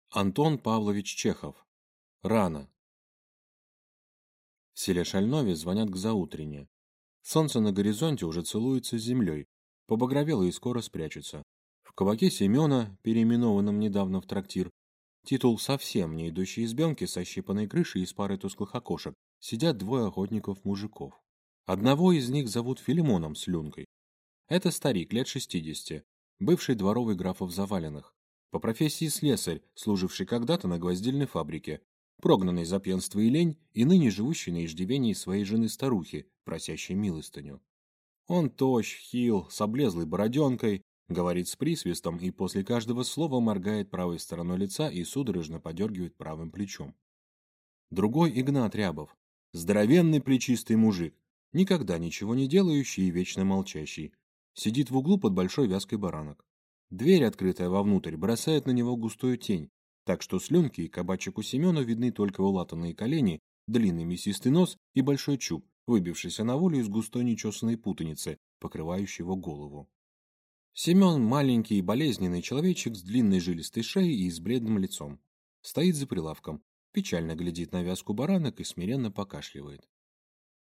Аудиокнига Рано!